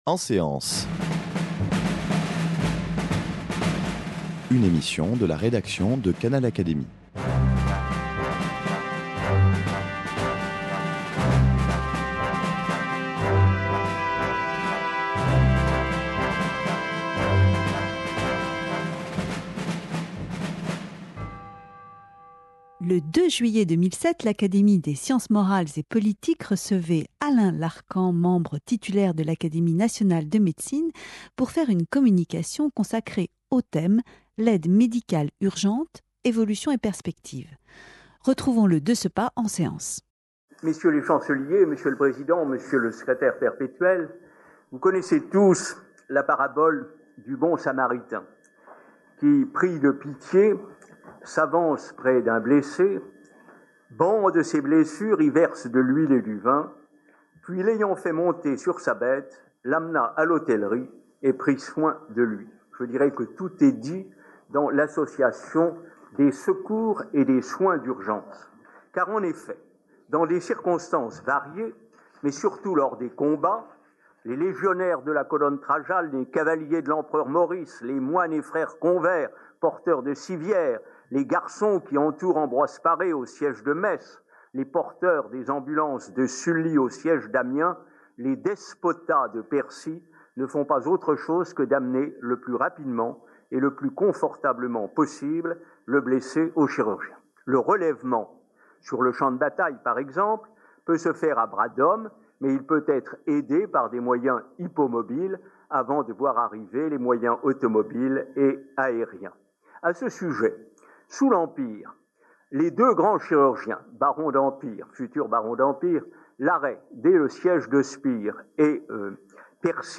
communication devant l'Académie des sciences morales et politiques